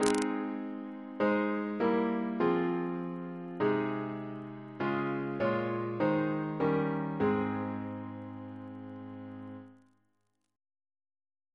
CCP: Chant sampler
Single chant in A♭ Composer: Vincent Novello (1781-1861) Reference psalters: H1940: 640